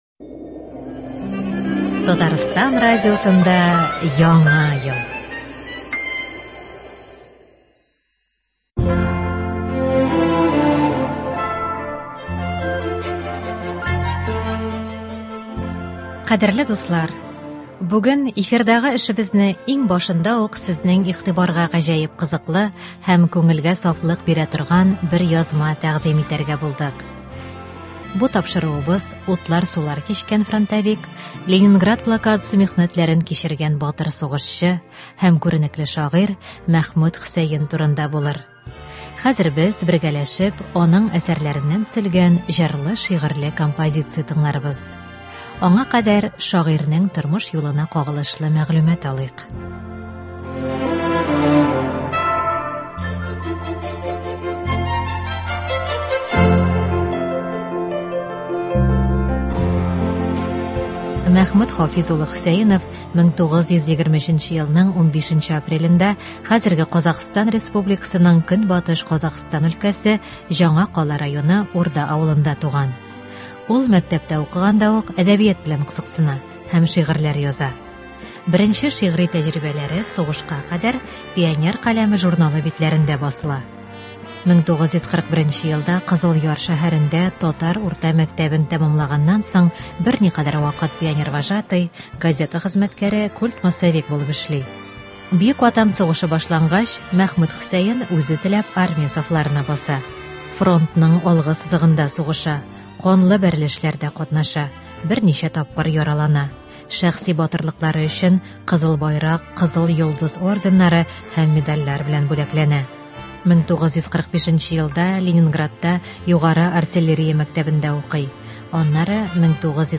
Шагыйрь Мәхмүт Хөсәен әсәрләреннән әдәби-музыкаль программа.
Бу тапшыруыбыз утлар-сулар кичкән фронтовик, Ленинград блокадасы михнәтләрен кичергән батыр сугышчы һәм күренекле шагыйрь Мәхмүт Хөсәен турында булыр. Хәзер без бергәләшеп аның әсәсләреннән төзелгән җырлы-шигырьле композиция тыңларбыз.